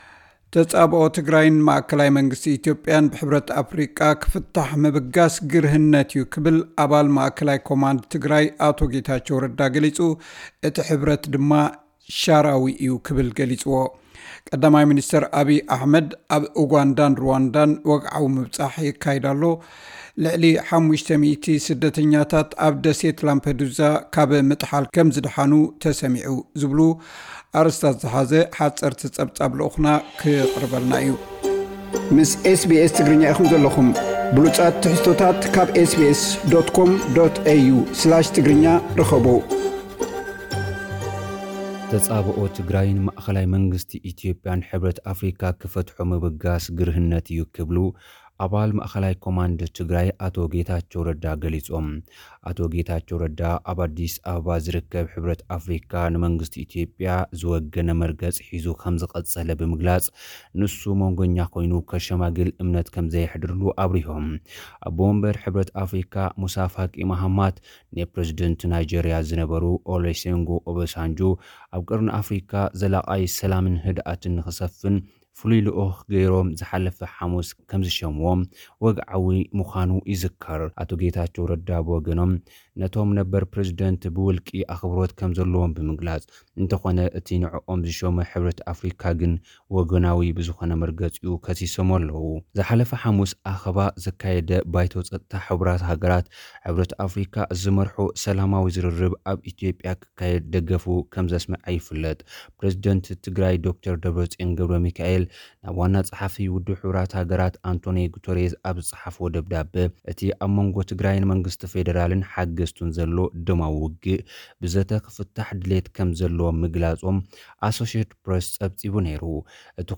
ሓጸርቲ ጸብጻባት፥ ቀዳማይ ሚንስተር ኣብይ ኣሕመድ ኣብ ኡጋንዳን ሩዋንዳን ወግዓዊ ምብጻሕ የካይድ ኣሎ። ልዕሊ 500 ስደተኛታት ኣብ ደሴት ላምፐዱስያ ካብ ምጥሓል ከም ዝደሓኑ ተሰሚዑ። ዝብሉ ኣርእስታት ዝሓዘ ሓጸርቲ ጸብጻባት ልኡኽና ከቕርበልና እዩ።